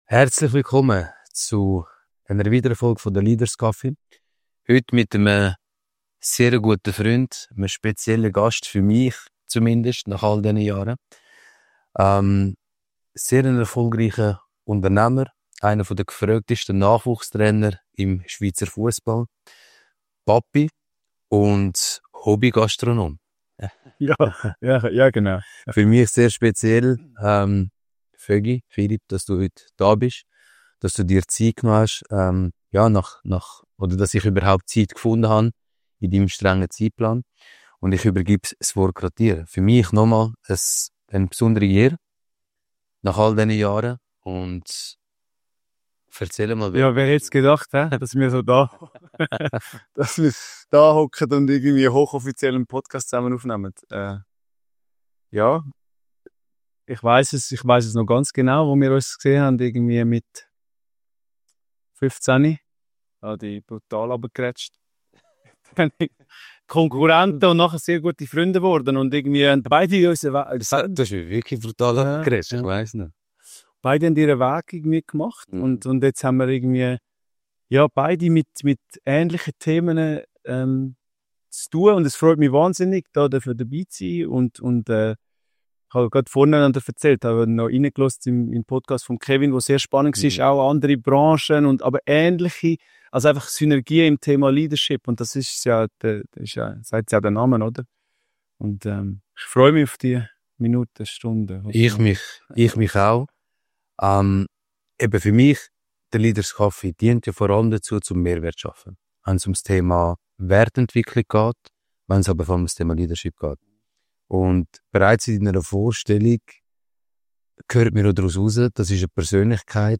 Erfahre in dieser Folge, wie er seine Mannschaft führt, inspiriert und motiviert – und bekomme Einblick in spannende Methoden und Prinzipien, um das Beste aus einem Team herauszuholen. Freu dich auf ein inspirierendes Gespräch über Leadership, Coaching und die Kunst, in verschiedenen Lebensbereichen erfolgreich zu sein – und warum der Mensch und das Vertrauen in ihn die Grundvoraussetzung für Erfolg im Fussball und generell im Leben sind.